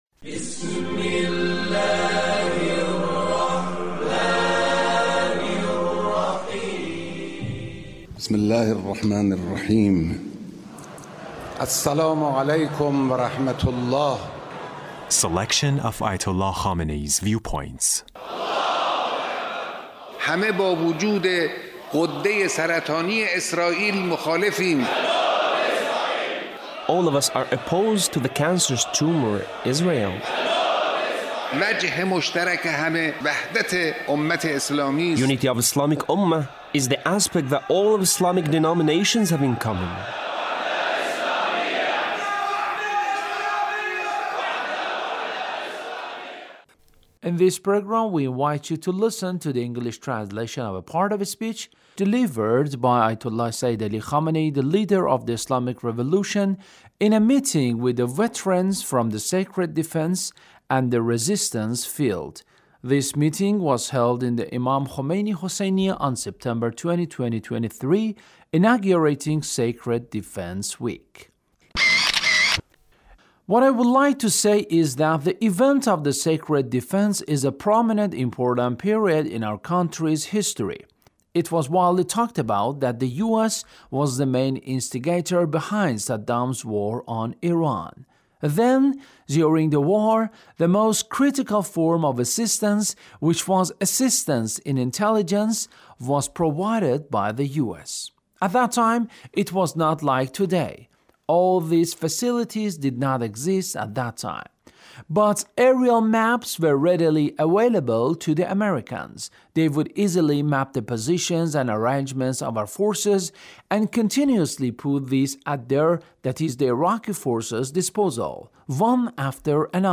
Leader's Speech on Sacred Defense